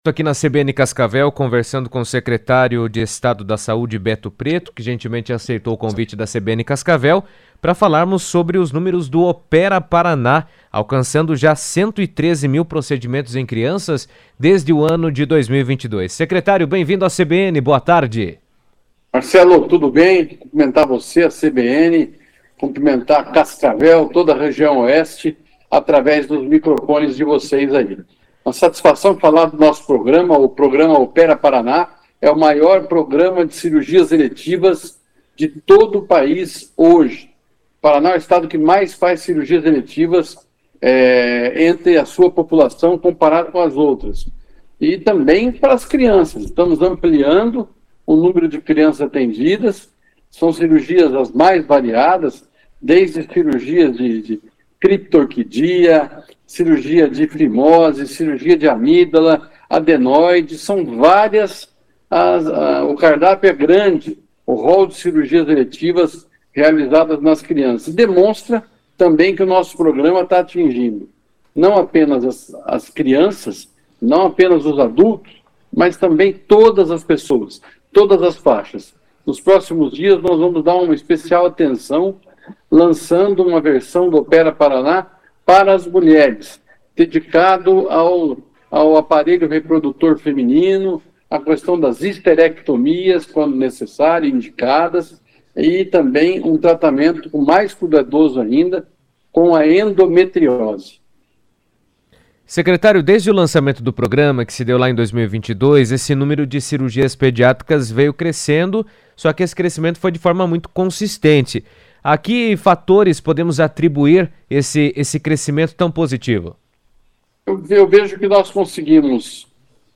O programa Opera Paraná já realizou mais de 113 mil procedimentos cirúrgicos em crianças desde 2022, garantindo atendimento rápido e especializado na rede pública de saúde. Beto Preto, Secretário de Estado da Saúde, comentou na CBN sobre a importância da iniciativa, destacando que o programa contribui para reduzir filas e ampliar o acesso de crianças a cirurgias essenciais em todo o estado.